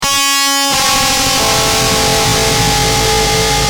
Screamb2.wav